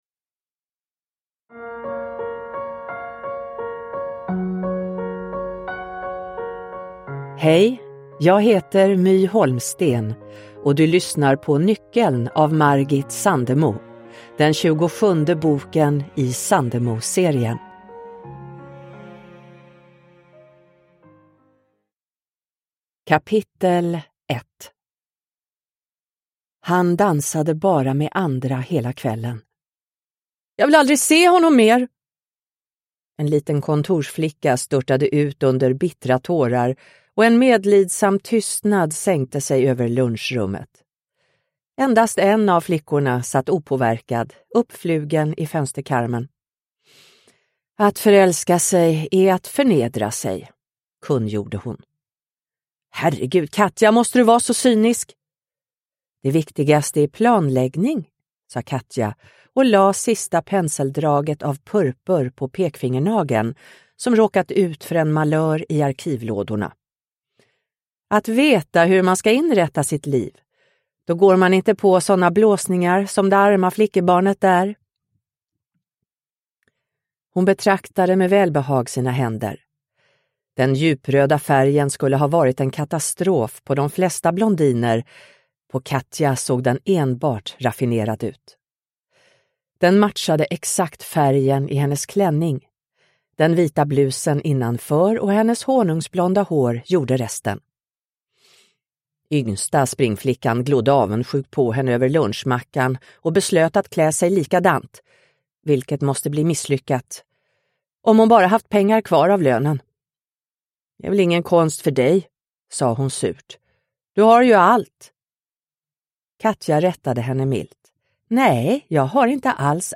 Nyckeln – Ljudbok – Laddas ner
Sandemoserien är en unik samling fristående romaner av Margit Sandemo, inlästa av några av våra starkaste kvinnliga röster.